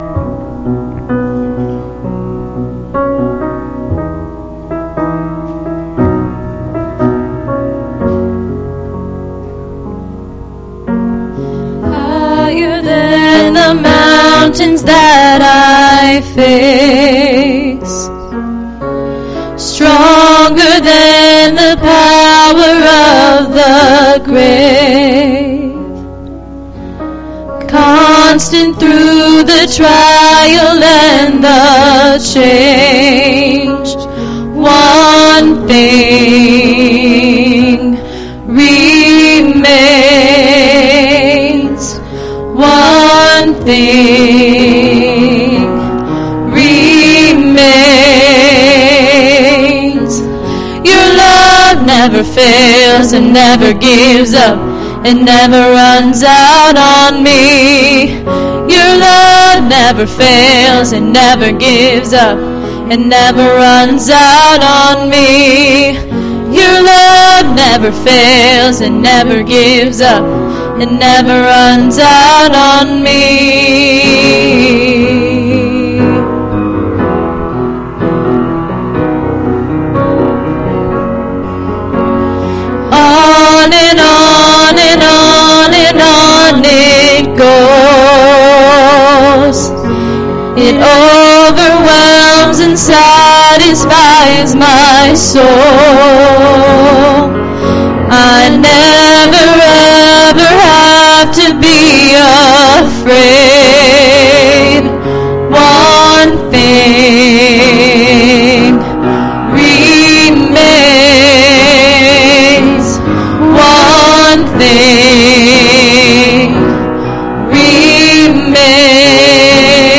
Please, click on the arrow to hear this week's service.